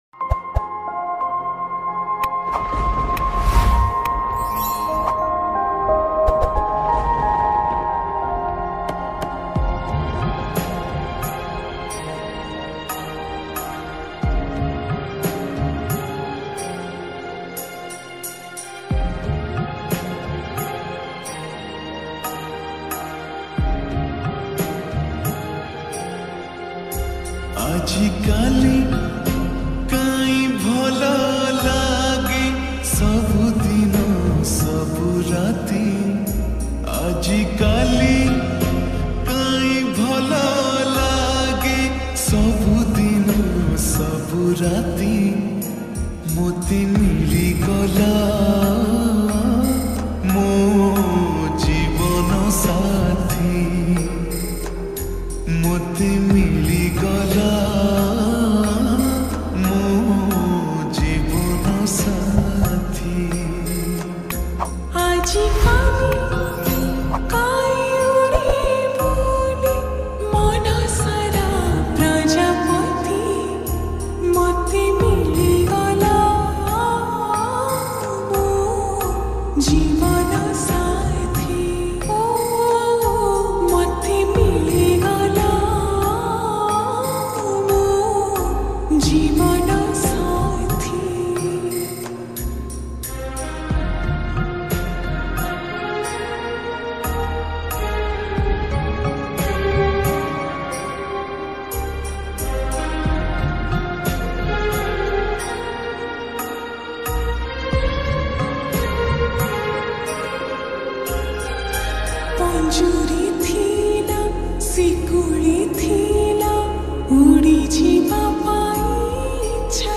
odia lofi song Songs Download